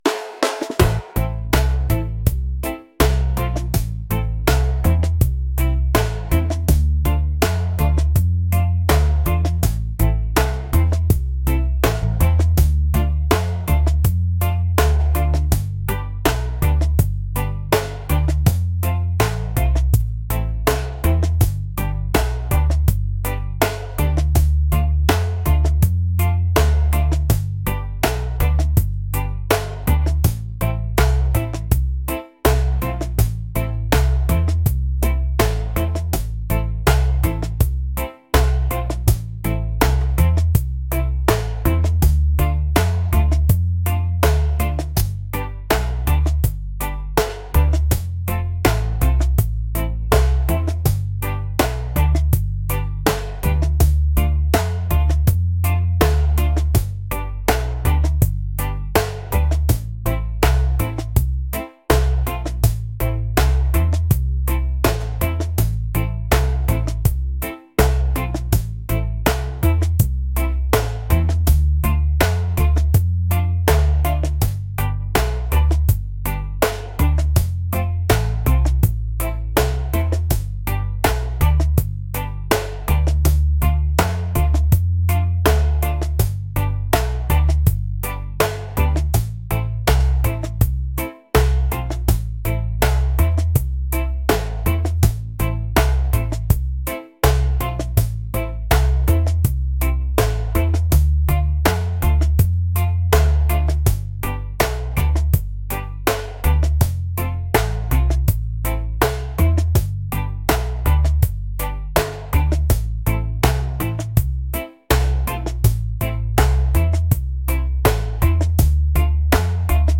reggae | laid-back | groovy